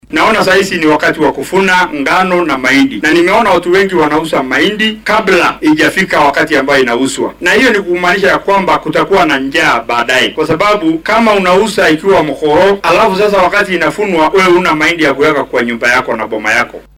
DHEGEYSO:Barasaabka Narok oo baaq u diray beeraleyda